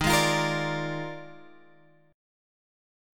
D# Major 7th Suspended 2nd Suspended 4th